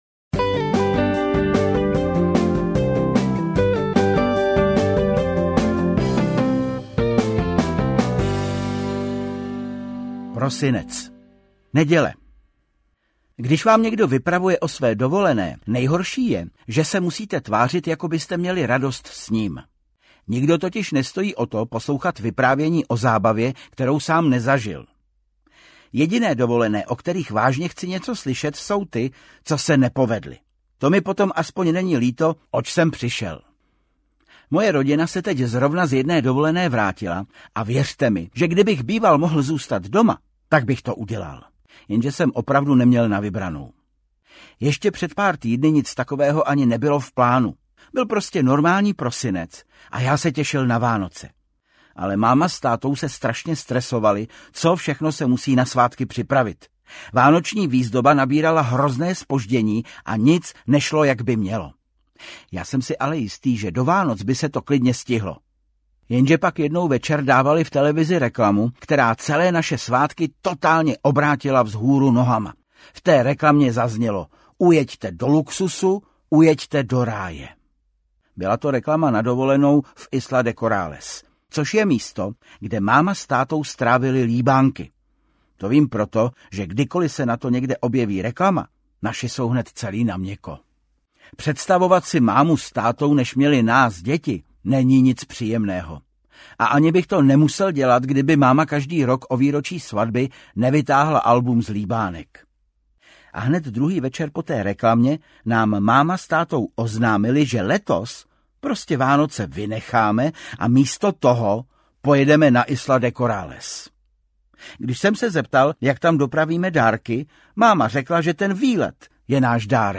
Deník malého poseroutky 12 audiokniha
Ukázka z knihy
• InterpretVáclav Kopta